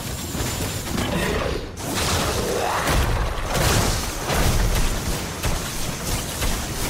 Hit Sound Meme